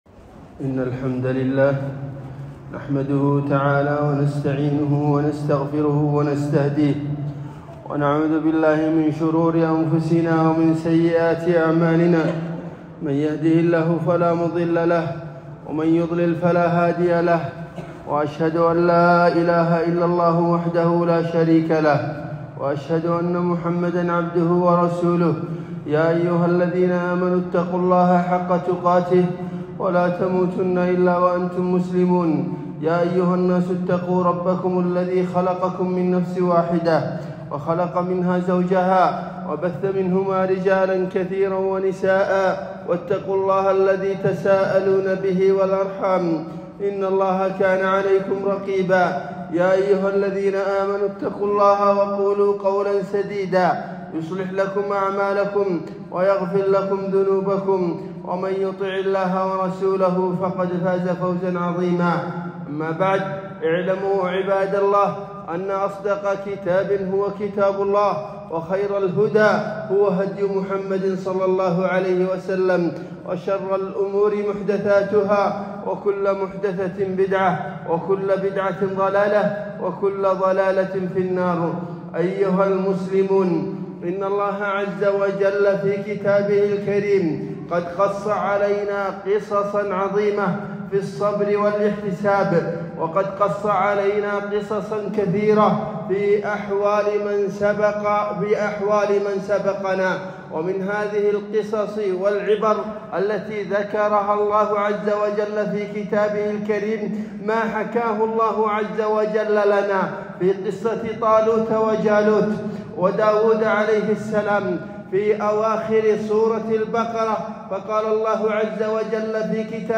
خطبة الجمعة - دروس وعبر من قصة طالوت وجالوت والعماليق،